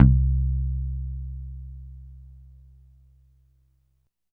85 BASS C3.wav